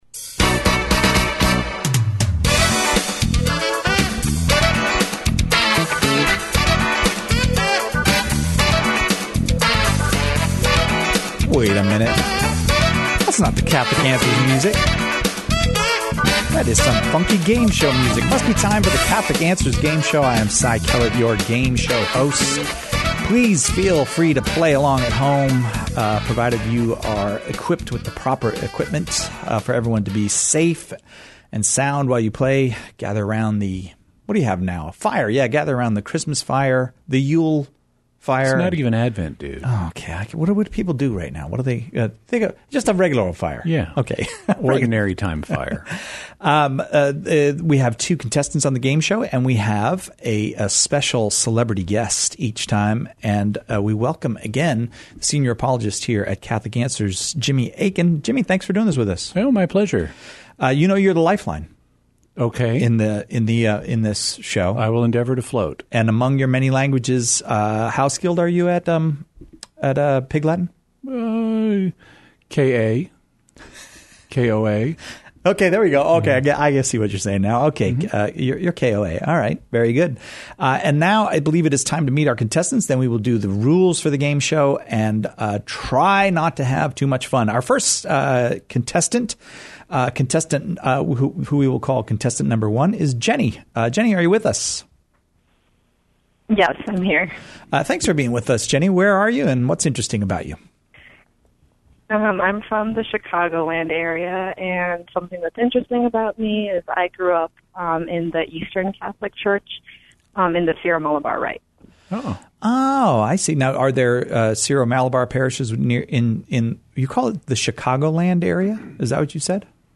Two listeners call in to answer Catholic trivia and complete for Catholic Answers Quiz Show glory!